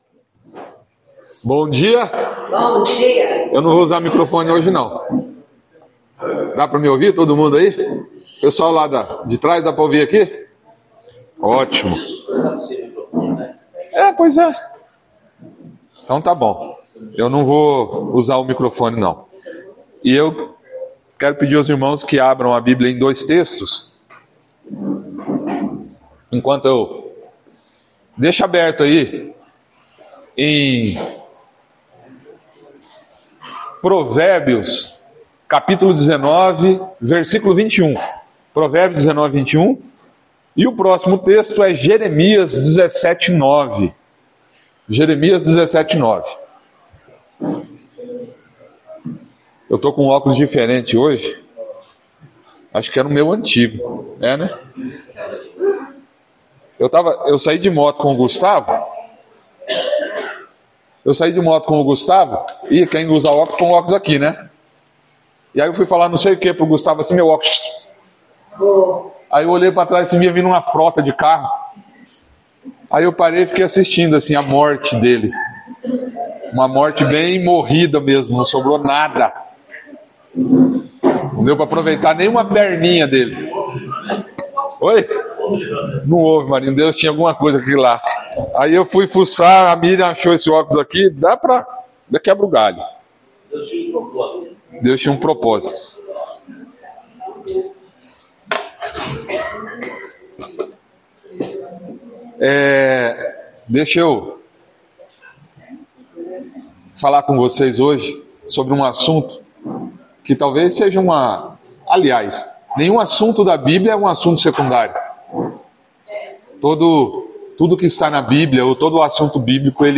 Palavra ministrada
no culto do dia 14/09/2025